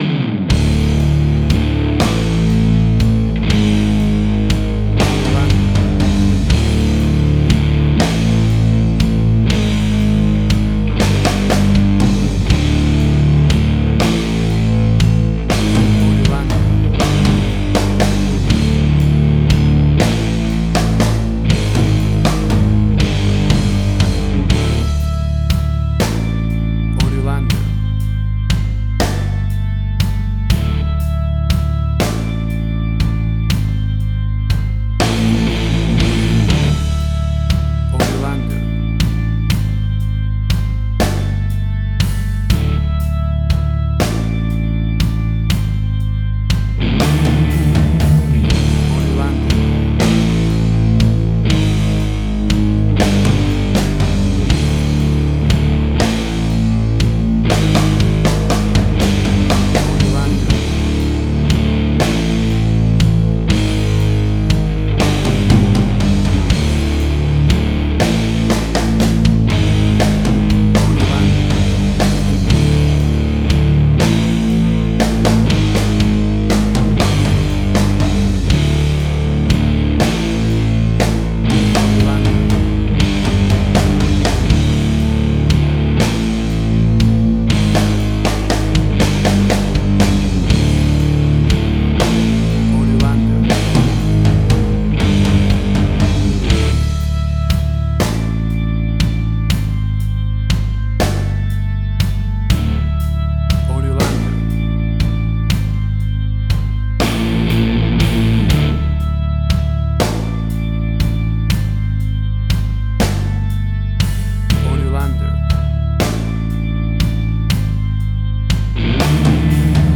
Hard Rock, Similar Black Sabbath, AC-DC, Heavy Metal.
WAV Sample Rate: 16-Bit stereo, 44.1 kHz
Tempo (BPM): 120